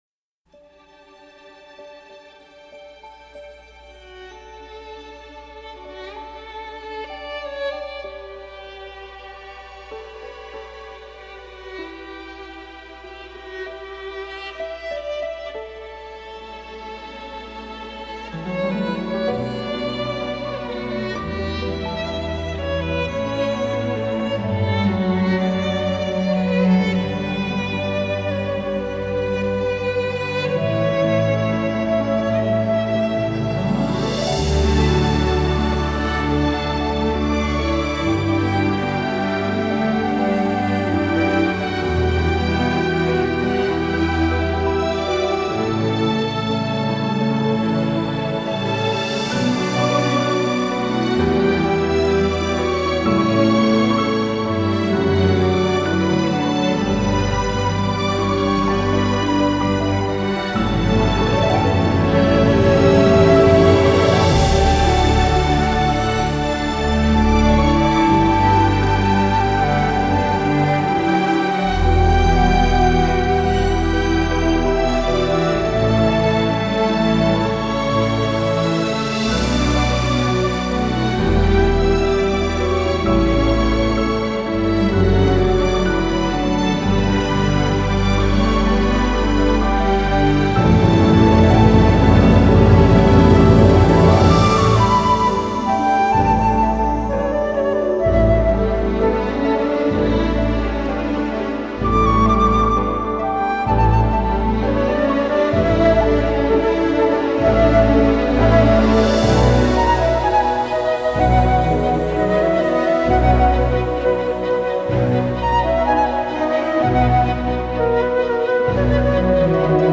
让心灵感受一片纯净与自然
绚丽复杂的旋律，也很少使用自然界的原声来强调主题，相反，那近